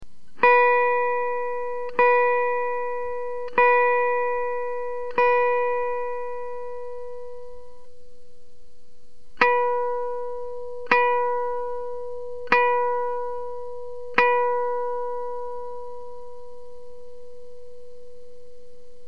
Curiously, the pitch of the regular tone at the twelfth fret and the pitch of the harmonic are the same:
fundvsharm.mp3